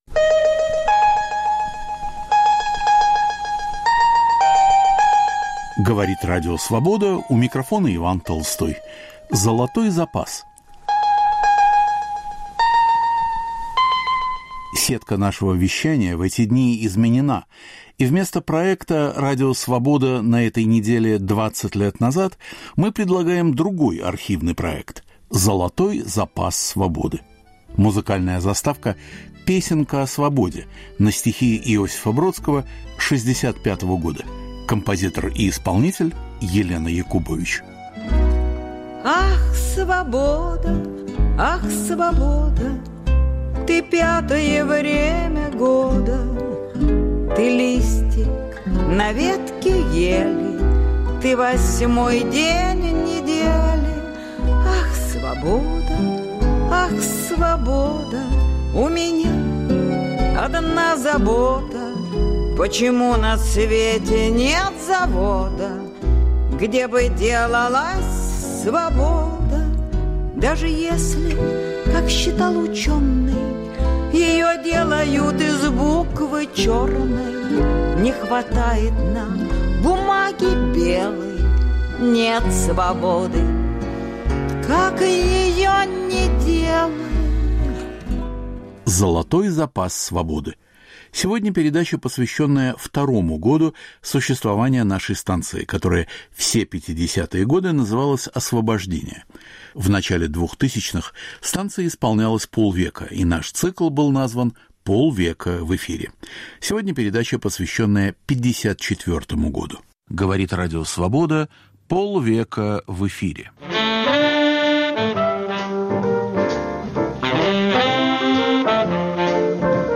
К 50-летию Радио Свобода. Фрагменты передач 1954-го.